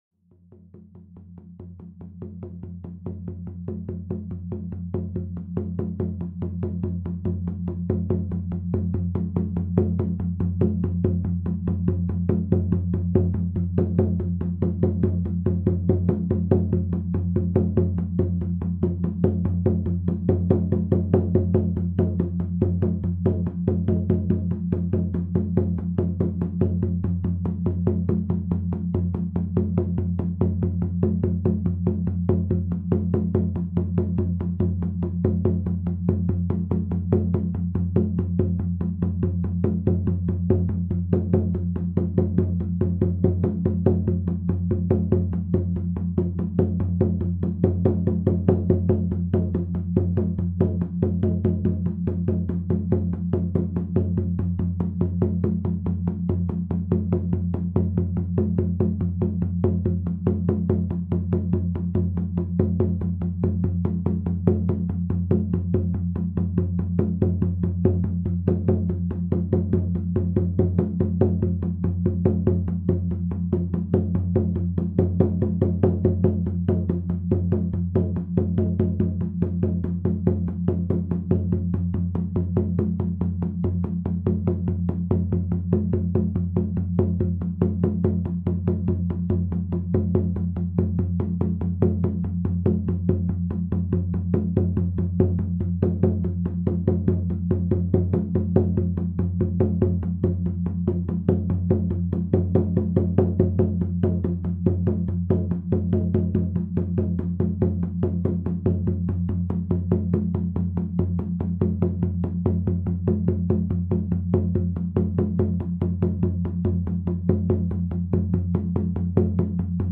Shaman Music
drum12.mp3